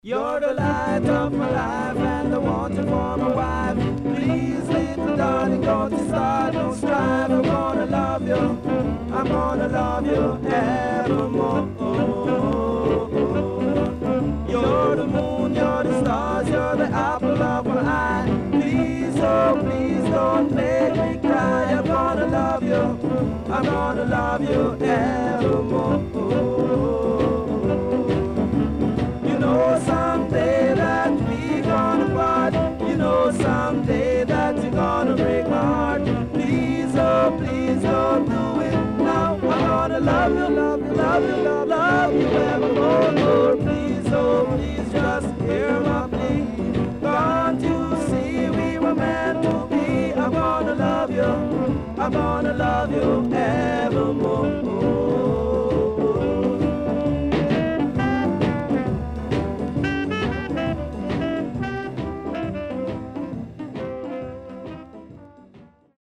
SIDE A:かるいヒスノイズあり、所々チリノイズ、プチノイズ入ります。